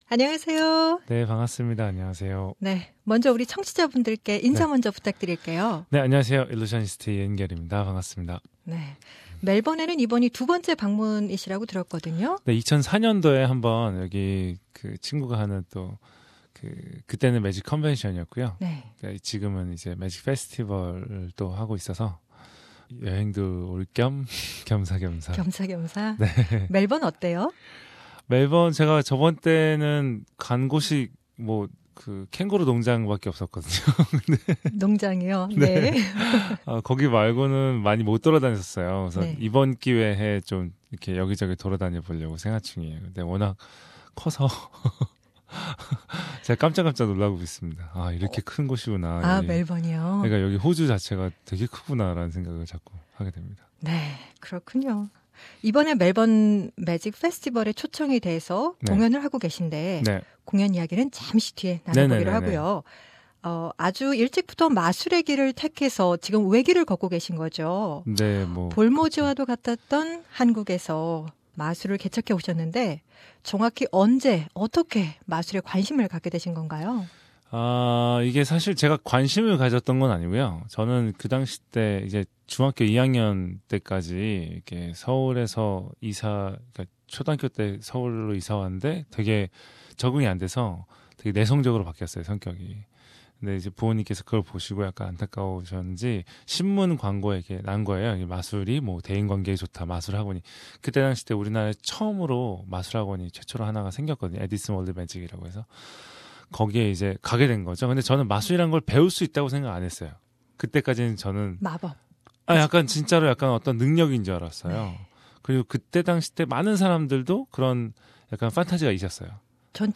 한국 최고의 일루셔니스트 이은결 씨로부터 마술사로서 그의 삶 그리고 그의 쇼 메타 일루션에 대해 들어본다. 상단의 다시 듣기 (PODCAST) 를 통해 이은결 씨와의 인터뷰를 확인하세요 .